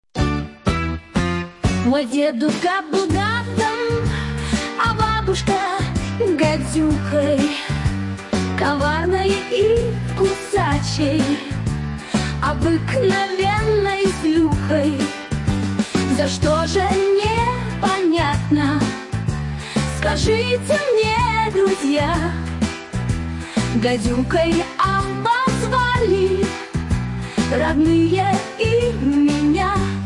Авторская песня для детей
Фрагмент 1-го варианта исполнения: